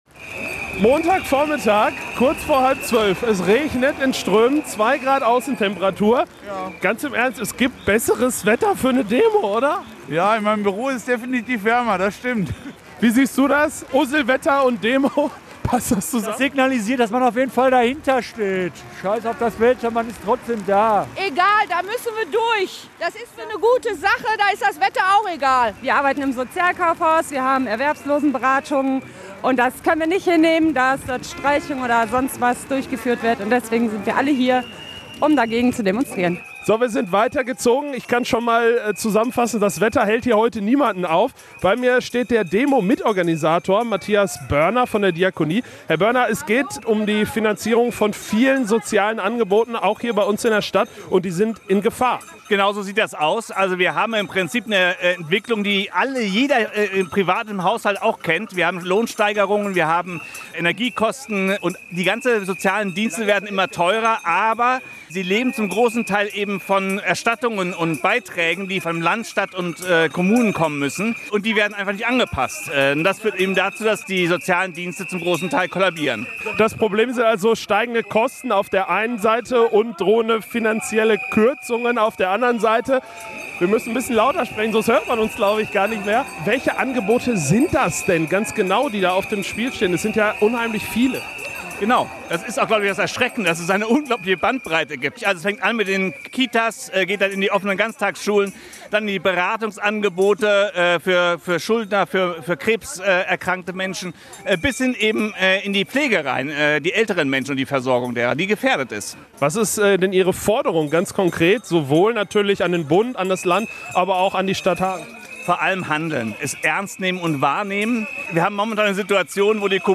reportage-sozialdemo-in-hagen.mp3